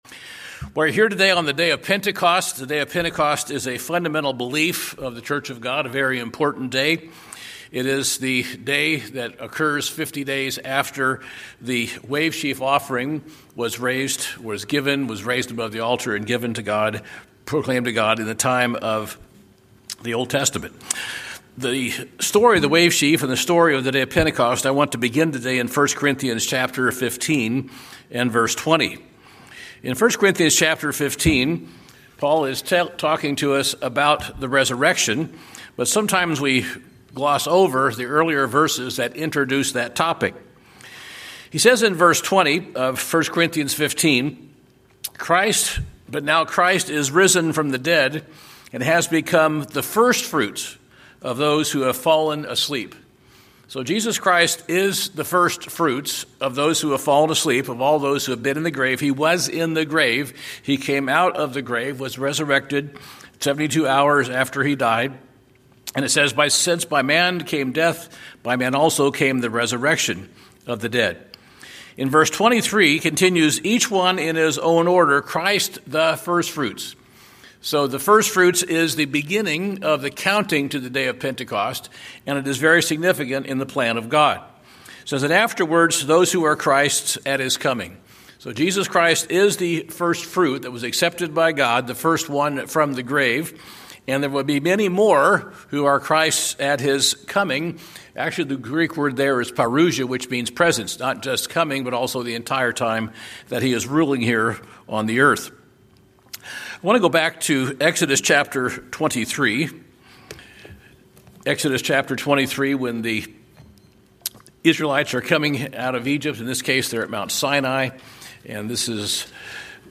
In this Holy Day message we have a description of both and how they're intrinsically linked together.
Given in Atlanta, GA Buford, GA